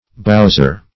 bouser - definition of bouser - synonyms, pronunciation, spelling from Free Dictionary Search Result for " bouser" : The Collaborative International Dictionary of English v.0.48: Bouser \Bous"er\, n. A toper; a boozer.